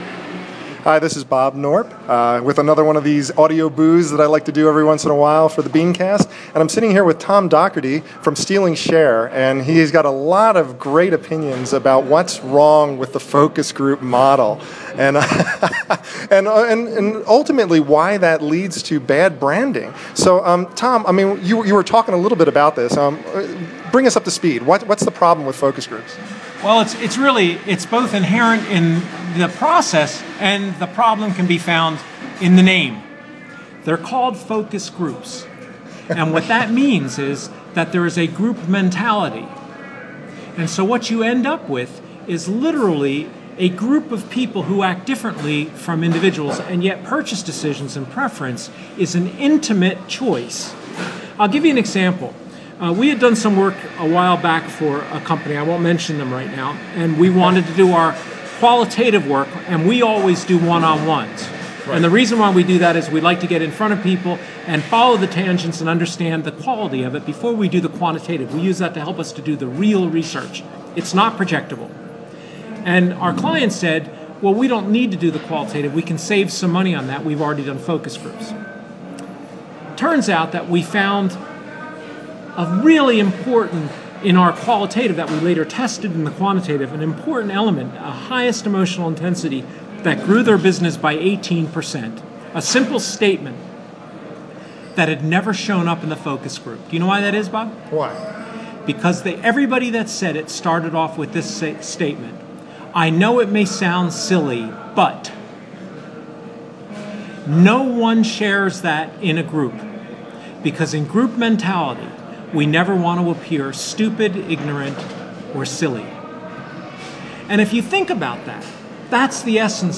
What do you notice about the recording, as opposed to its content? This audio clip cuts off